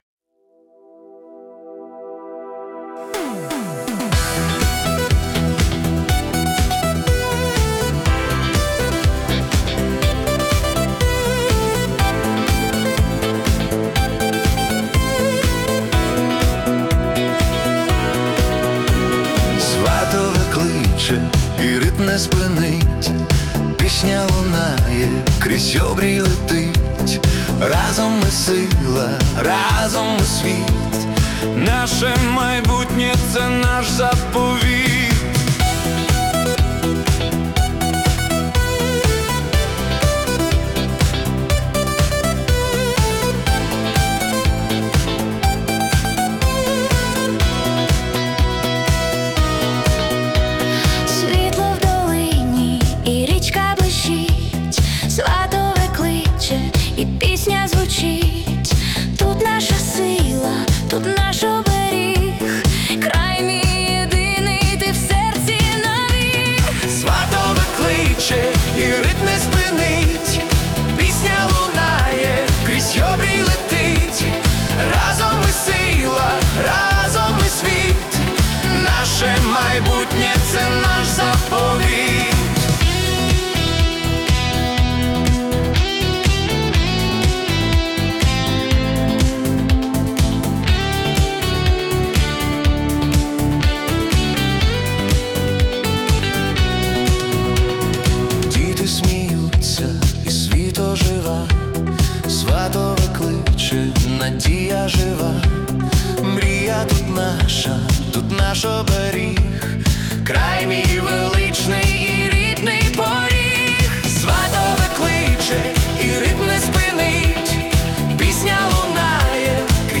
🎵 Жанр: Italo Disco / Anthem
це енергійний та надихаючий трек